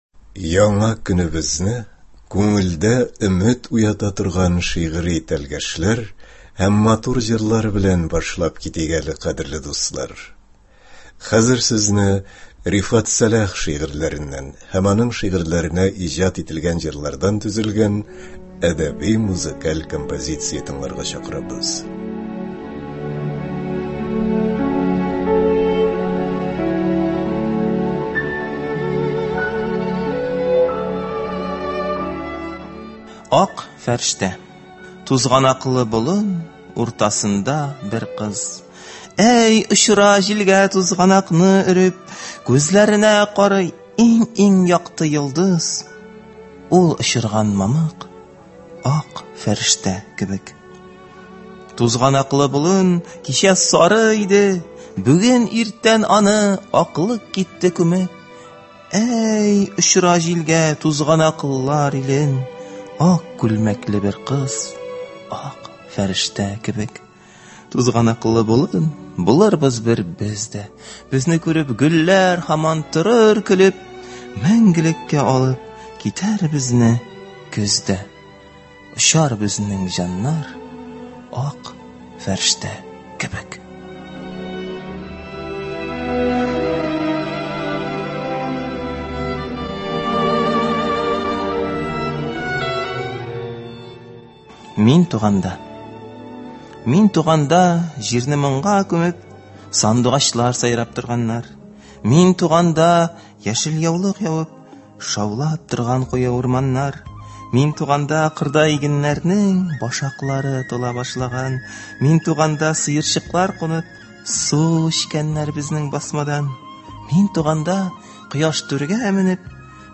Рифат Сәлах әсәрләреннән әдәби-музыкаль композиция.